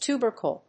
音節tu・ber・cle 発音記号・読み方
/t(j)úːbɚkl(米国英語), tjúːbəbɚkl(英国英語)/